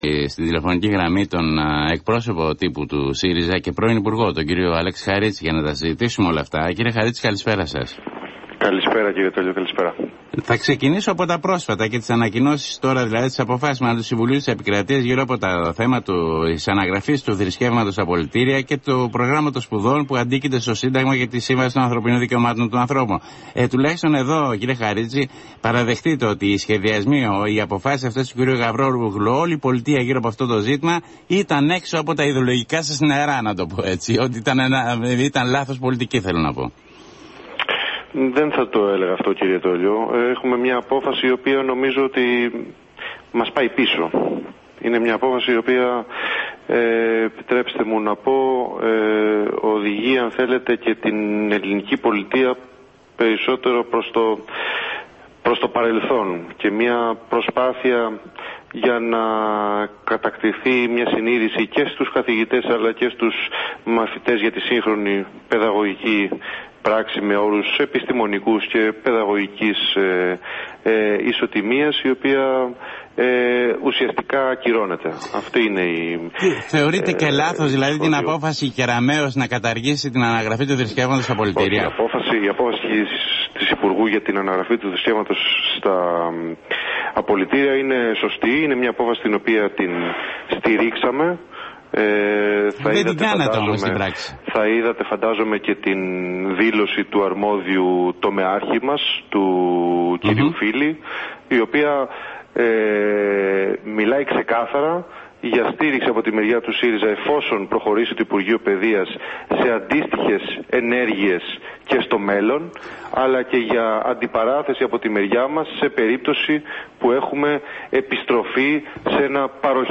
Για προσπάθεια χυδαίου αντιπερισπασμού της κυβέρνησης από το πραγματικό σκάνδαλο Νοvartis και σκευωρίας, έκανε λόγο ο εκπρόσωπος Τύπου του ΣΥΡΙΖΑ, Αλέξης Χαρίτσης, αναφερόμενος στην απόφαση της κοινοβουλευτικής πλειοψηφίας, να προχωρήσει σε συγκρότηση προανακριτικής επιτροπής σχετικά με την υπόθεση.